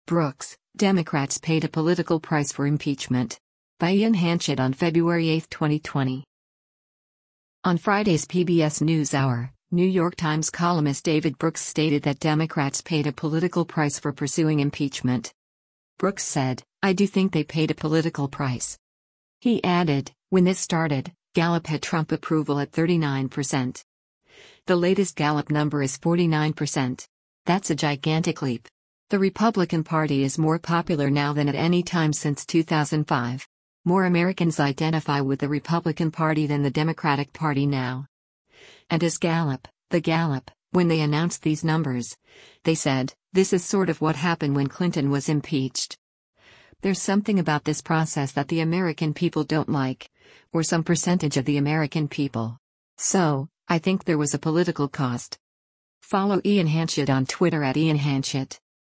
On Friday’s “PBS NewsHour,” New York Times columnist David Brooks stated that Democrats “paid a political price” for pursuing impeachment.